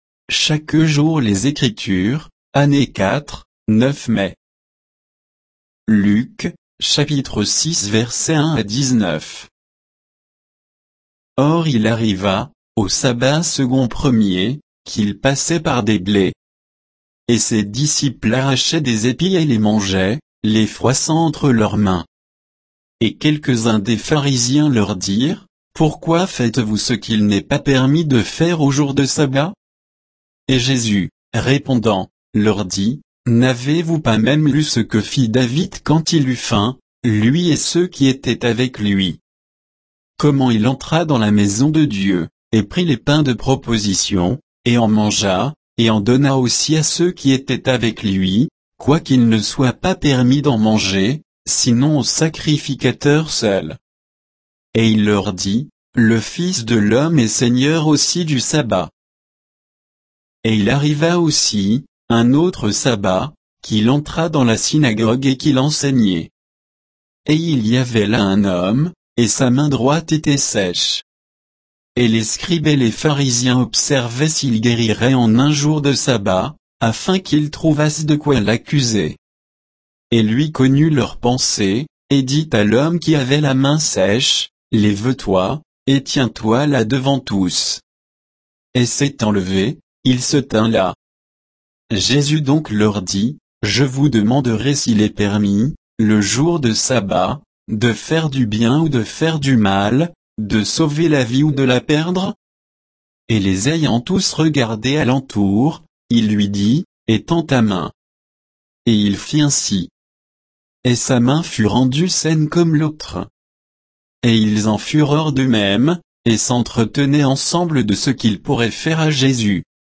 Méditation quoditienne de Chaque jour les Écritures sur Luc 6, 1 à 19